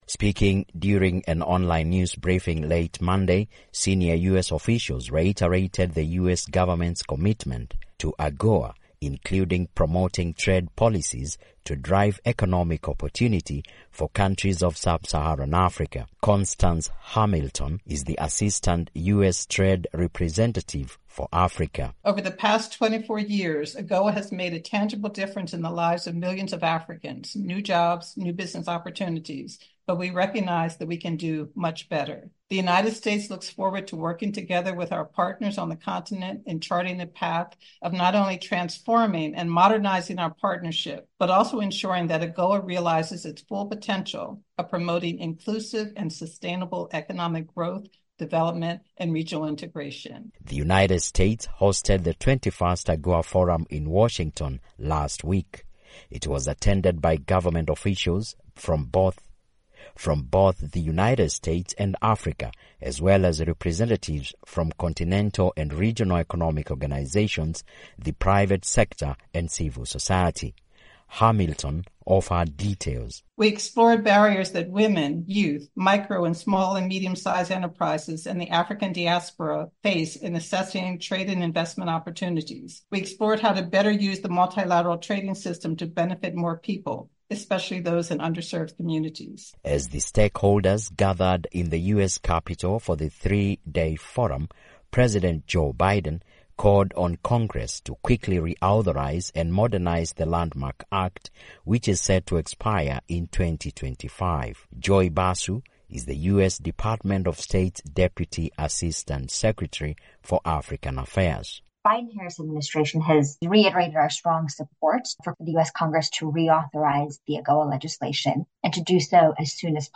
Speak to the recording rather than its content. reports from Nairobi, Kenya.